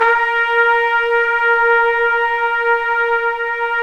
Index of /90_sSampleCDs/Roland LCDP06 Brass Sections/BRS_Tpts mp)f/BRS_Tps Velo-Xfd